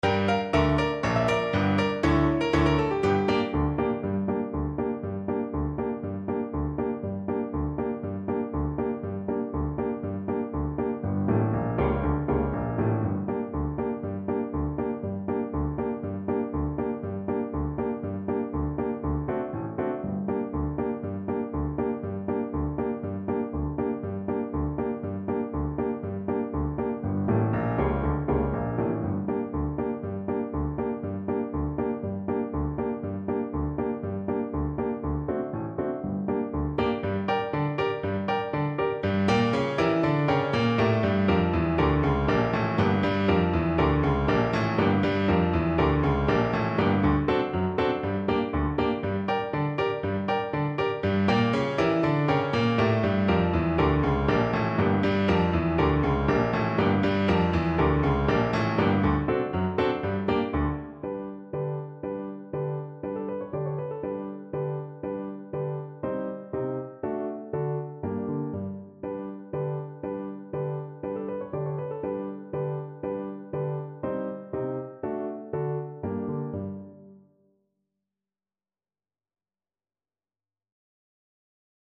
French Horn
D minor (Sounding Pitch) A minor (French Horn in F) (View more D minor Music for French Horn )
Moderate Klezmer = 120
2/4 (View more 2/4 Music)
Traditional (View more Traditional French Horn Music)
world (View more world French Horn Music)